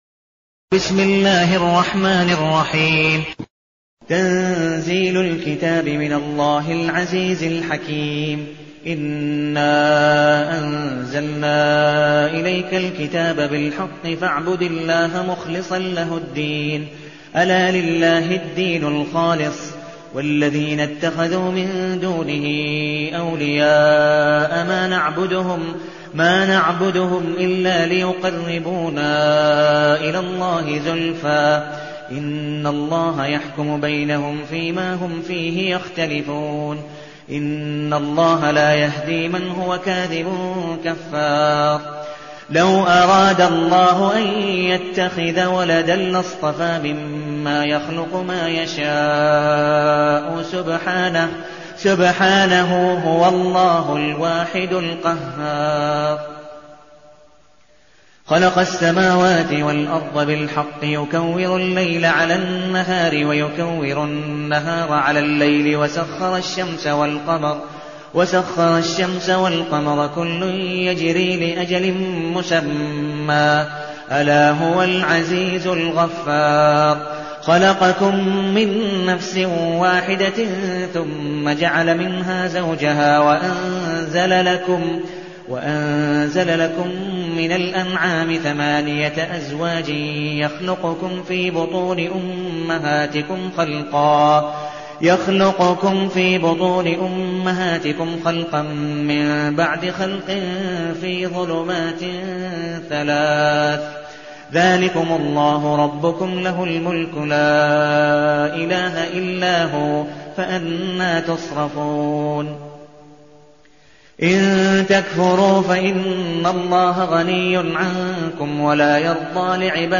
المكان: المسجد النبوي الشيخ: عبدالودود بن مقبول حنيف عبدالودود بن مقبول حنيف الزمر